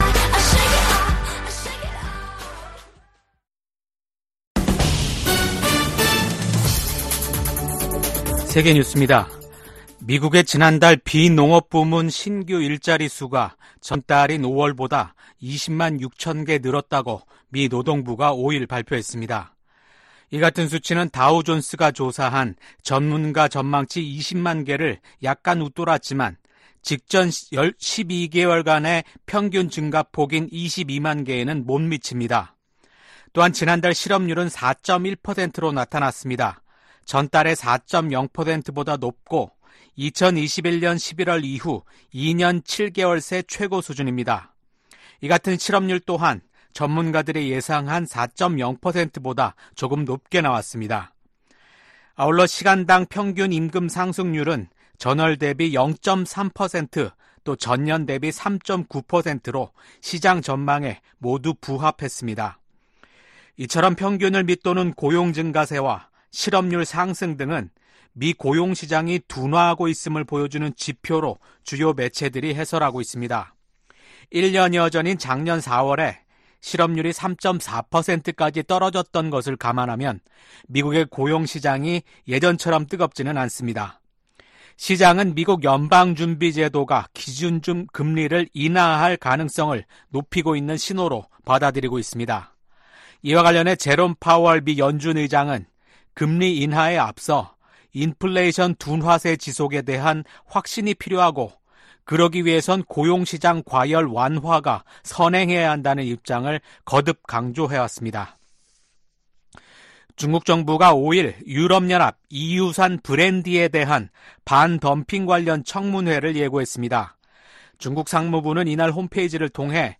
VOA 한국어 아침 뉴스 프로그램 '워싱턴 뉴스 광장' 2024년 7월 6일 방송입니다. 미 국무부는 북한 탄도미사일이 러시아의 우크라이나 공격에 사용됐다는 분석을 제시하며, 양국에 책임을 묻겠다고 경고했습니다. 북한을 비롯한 적성국들이 미국의 첨단 기술을 무단으로 사용하고 있는지 파악해 대응하도록 하는 법안이 미 하원에서 발의됐습니다.